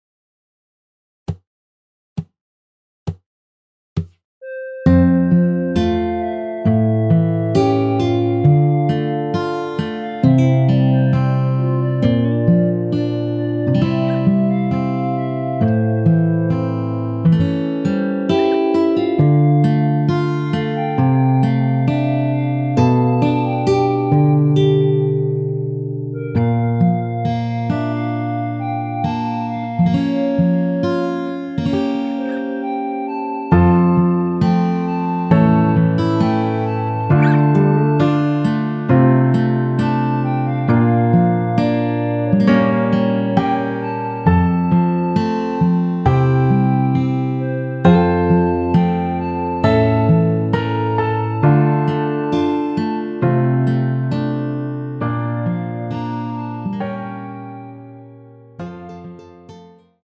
원키 멜로디 포함된 MR입니다.(미리듣기 확인)
앞부분30초, 뒷부분30초씩 편집해서 올려 드리고 있습니다.
중간에 음이 끈어지고 다시 나오는 이유는
(멜로디 MR)은 가이드 멜로디가 포함된 MR 입니다.